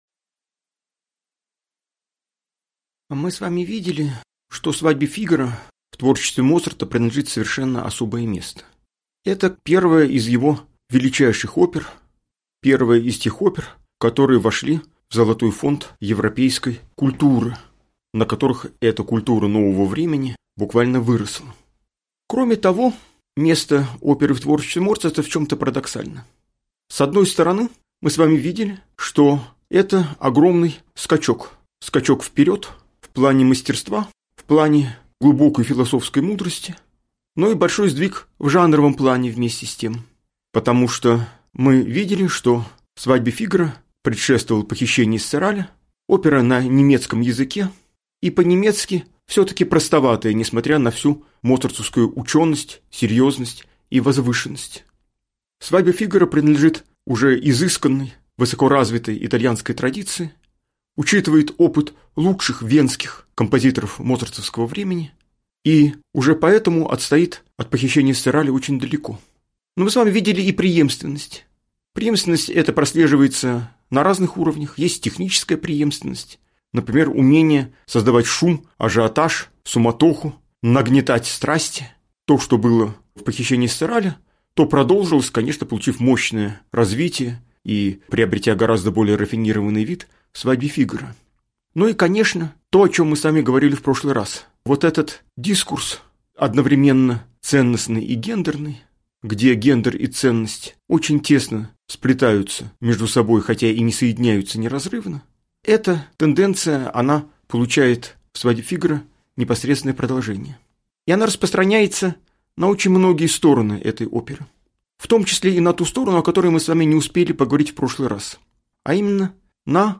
Аудиокнига Лекция «Свадьба Фигаро». Карнавал без масок: ангелы любви» | Библиотека аудиокниг